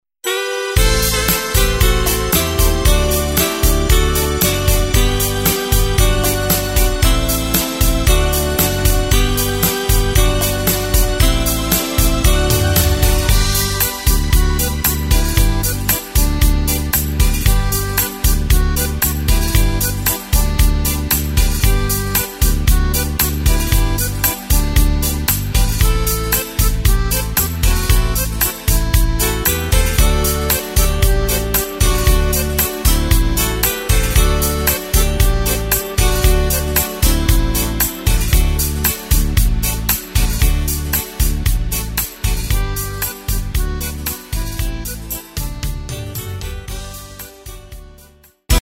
Takt:          4/4
Tempo:         115.00
Tonart:            G
Playback mp3 Mit Drums